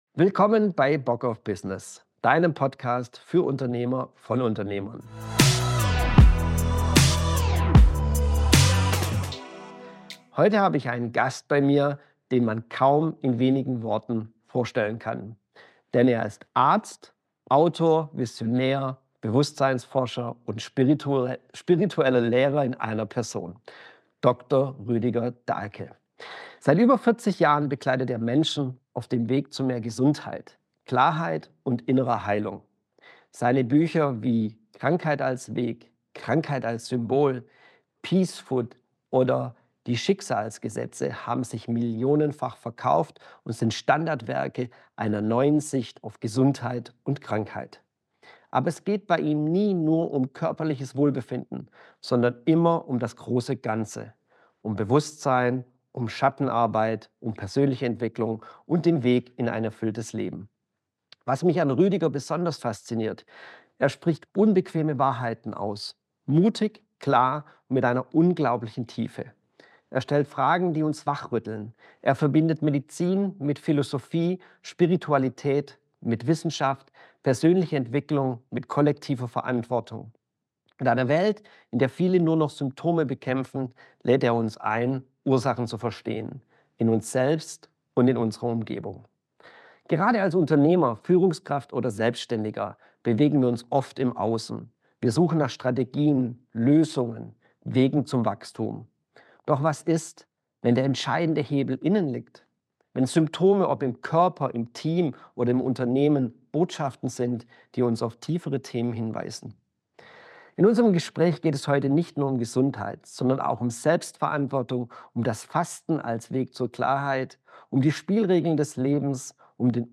#067 - Rüdiger Dahlke als Gast bei Bock auf Business ~ Bock auf Business - Unternehmerstories unzensiert Podcast